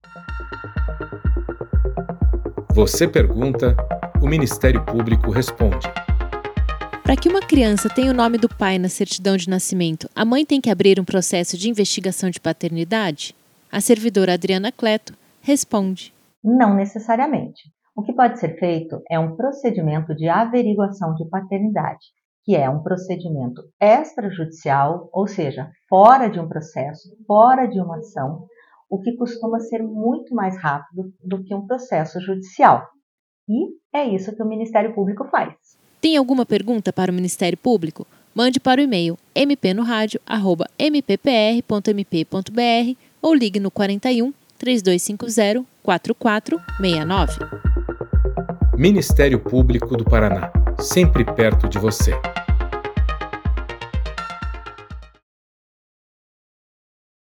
Você pergunta, o MP responde. Em áudios curtos, de aproximadamente um minuto, procuradores e promotores de Justiça esclarecem dúvidas da população sobre questões relacionadas às áreas de atuação do Ministério Público.